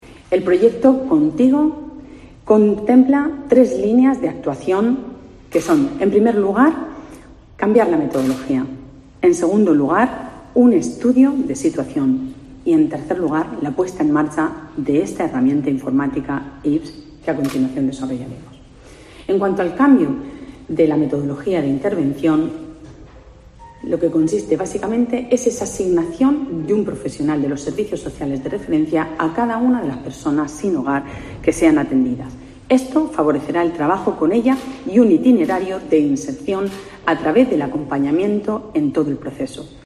Pilar Torres, concejala de Bienestar Social, Familia y Salud